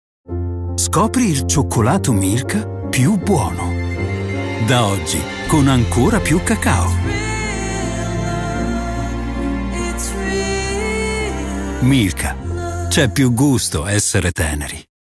Male
Authoritative, Character, Confident, Cool, Deep, Warm, Versatile
Neutral Italian with perfect pronunciation and Italian dialect inflections.
Microphone: AKG C414 XL 2 - Rhode NT2 vintage